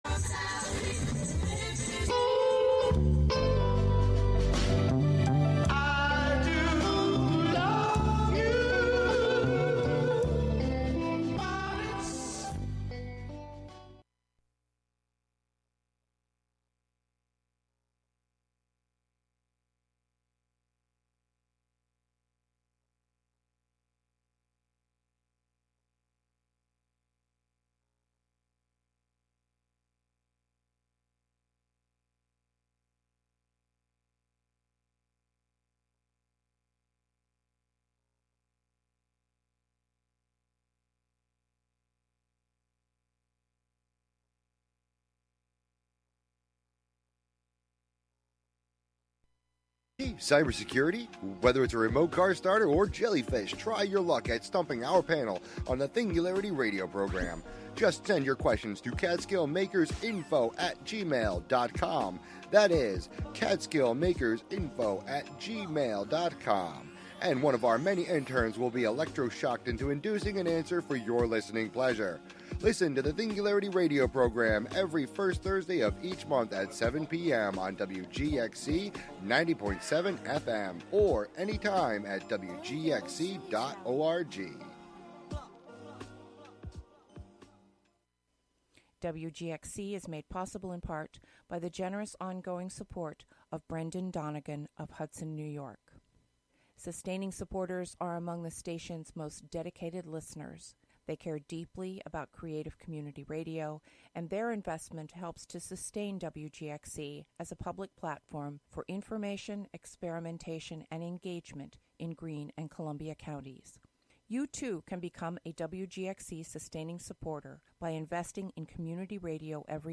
short stories and fables from around the world that are available in print will be the only topic here.
just the fictions read for the listener as best as i can read them.